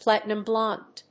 アクセントplátinum blónde